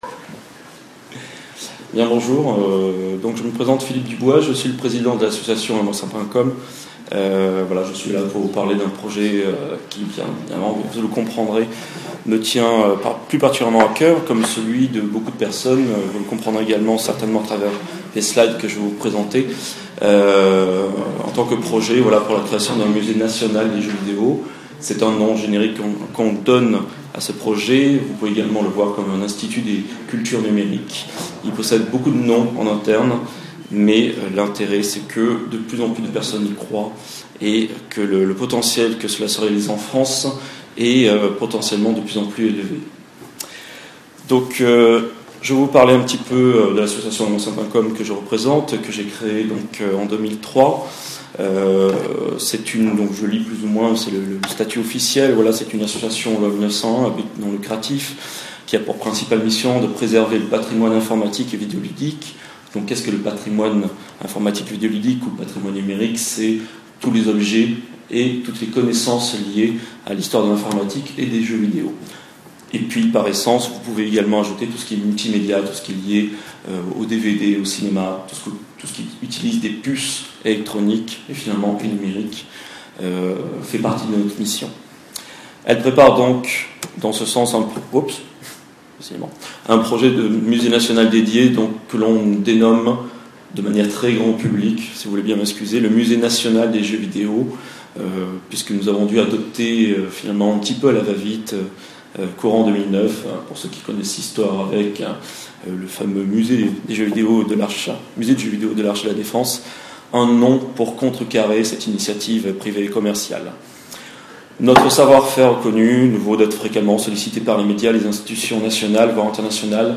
Cette conférence a eu lieu dans le cadre de "pixellissime Game Heroes" à la BMVR Alcazar à Marseille.